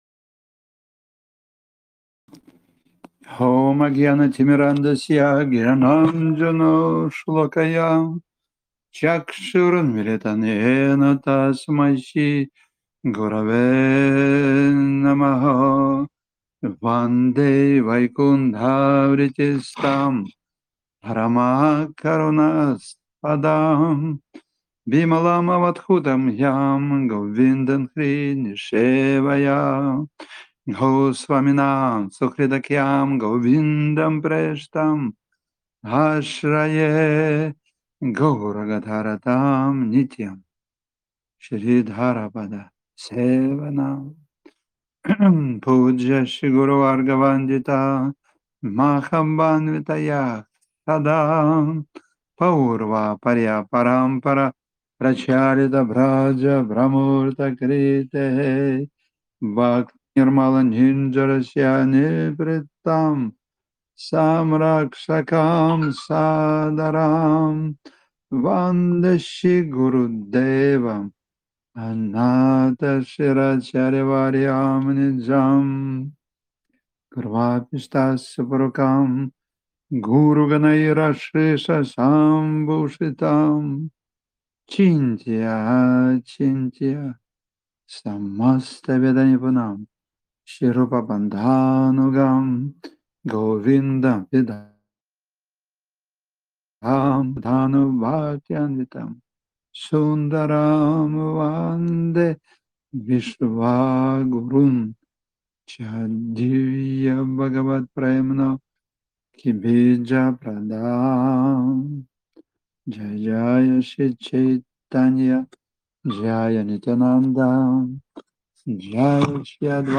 Гупта Говардхан Сева Ашрам на Волге
Лекции полностью